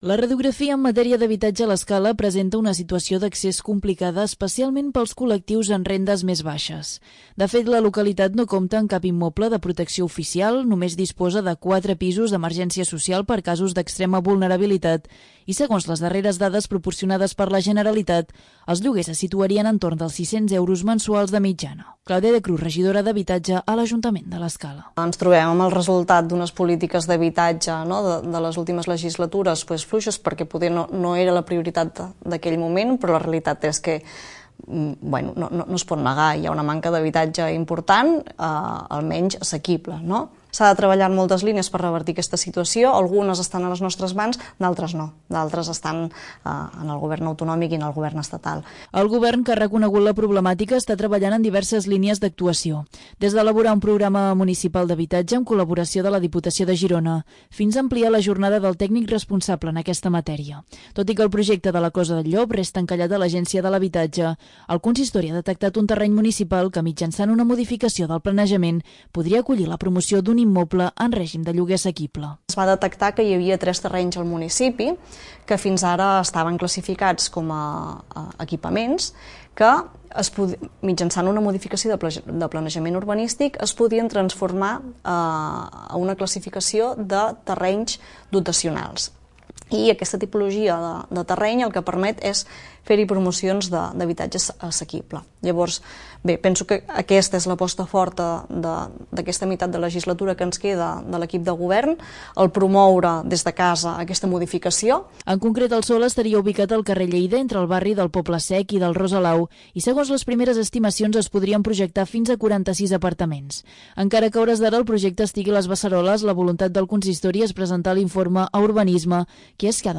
Durant l'entrevista al programa 'La Casa de la Vila', ha explicat que el sistema atorga a un promotor extern la responsabilitat de construir i gestionar el futur edifici, encara que la propietat continuaria sent municipal.